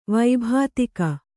♪ vaibhātika